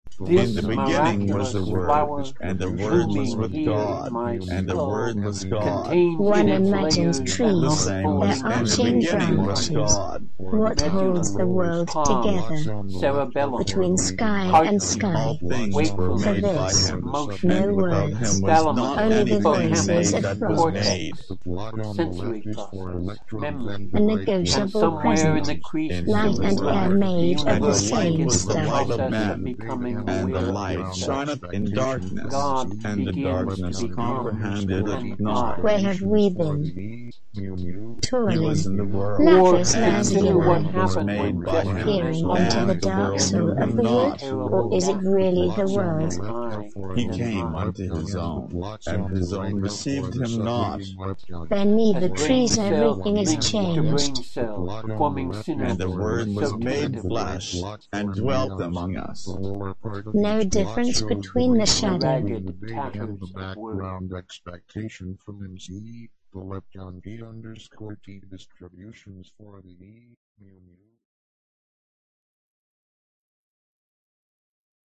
One of the ways of doing this is through simultaneous voices. “Collider” uses the opening of John’s gospel from the King James version of the Bible, part of a series of set-up instructions for an experiment on the Large Hadron Collider at CERN, and two original pieces. Although in many cases, my multi-voice pieces are presented as live performance, relying on chance for the final outcome, “Collider” was deliberately manipulated using synthesized voices and multi-track editing.